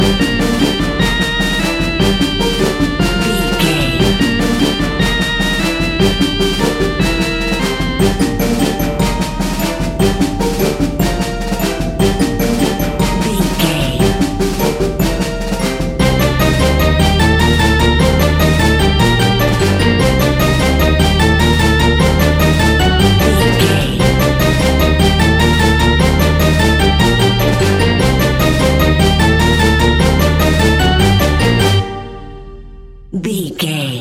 In-crescendo
Aeolian/Minor
Fast
ominous
dramatic
eerie
energetic
brass
synthesiser
drums
strings
horror music